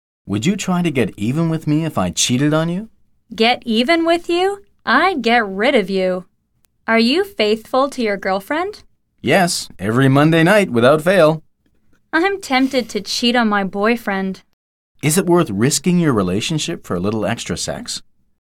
來聽老美怎麼說？